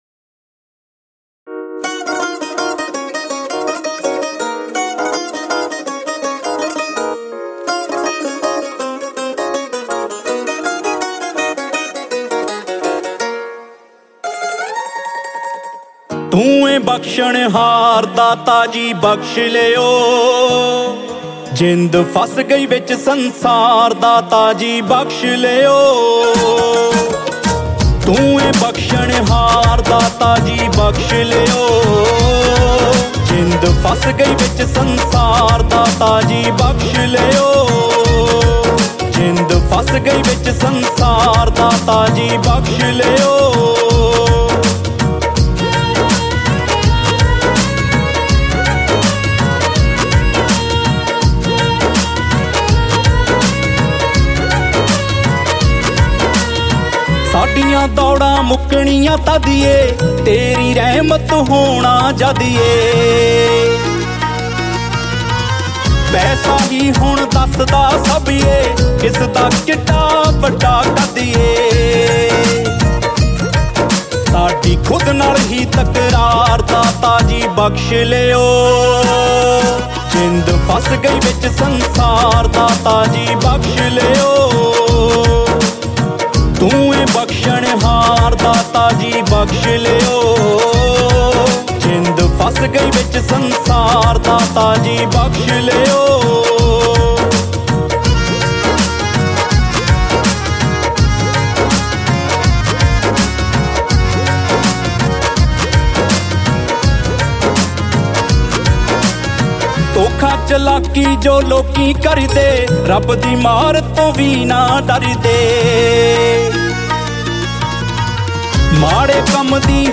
Category: Shabad Gurbani